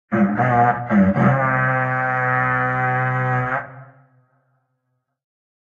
raidhorn_03.ogg